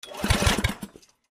in_generator_pull_03_hpx
Electric generator starts and sputters then shuts off. Electric Generator Motor, Generator Engine, Generator